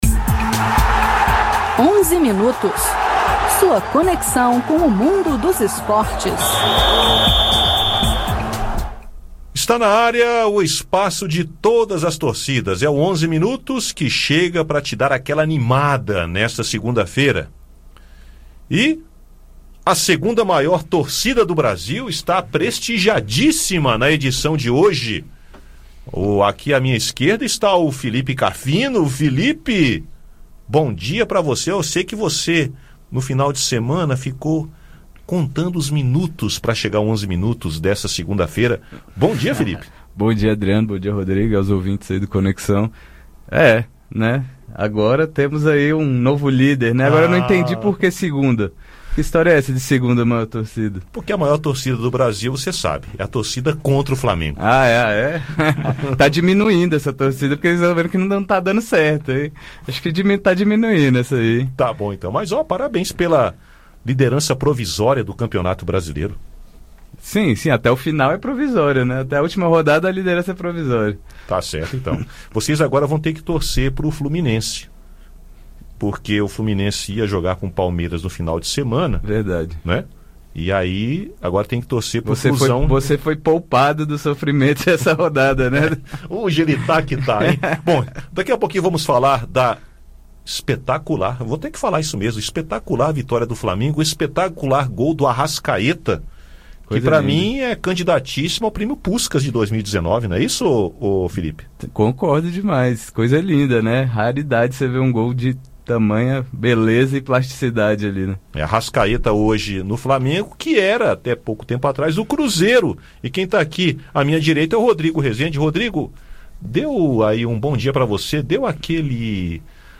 Também são assunto no programa de hoje os jogos do Brasileirão no final de semana. Ouça o áudio com o bate-papo.